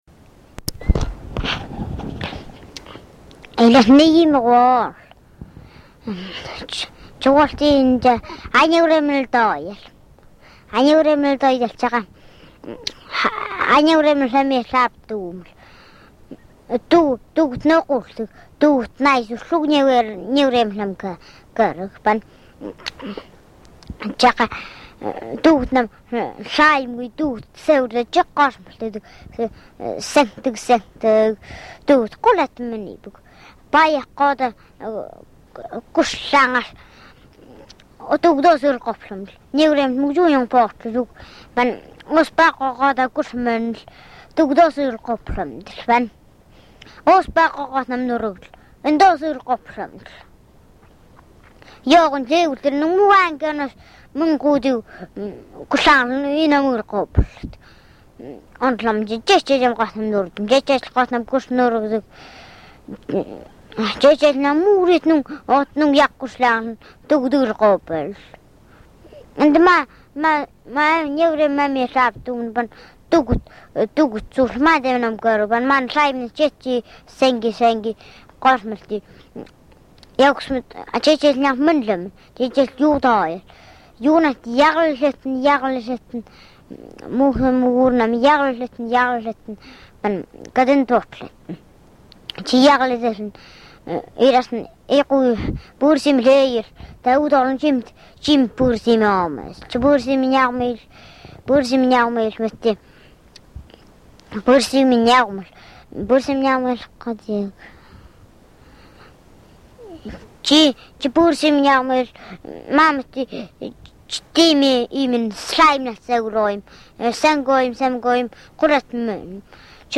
surgut khanty (SK)